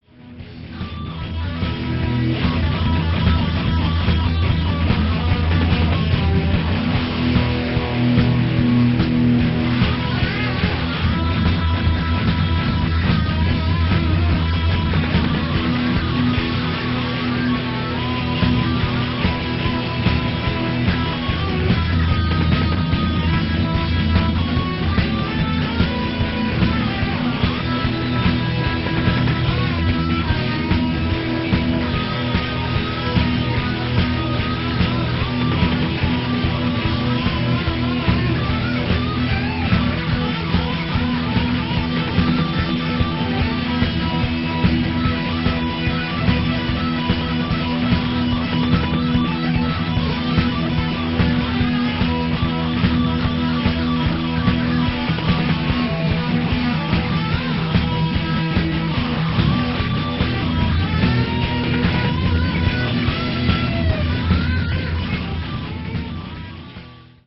Fasty is the meanest and most aggressive, yet very joyfull piece that I've ever done. It is very outgoing.
A friend of mine says that it reminds him of a tiger running loose downtown in some metropolis.
She's wrong! it is actually a very very happy song, despite it's apparent aggressiveness.